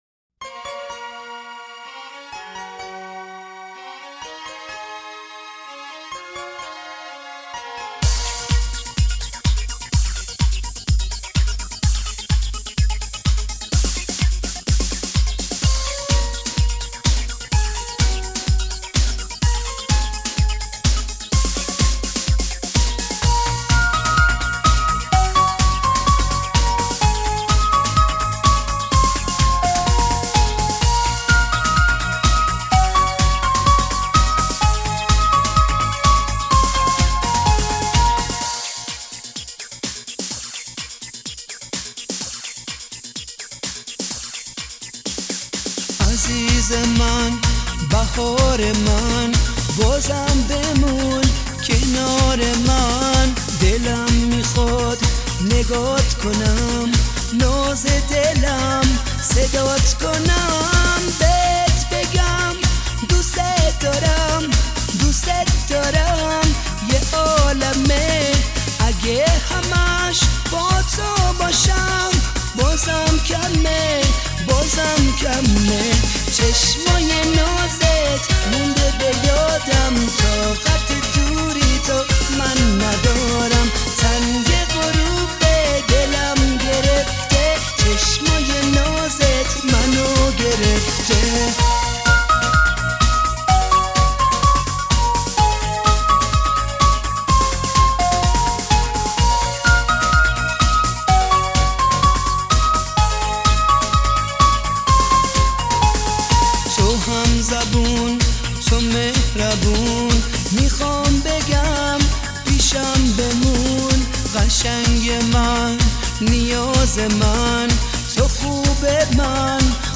آهنگ شاد ایرانی
آهنگ نوستالژی ایرانی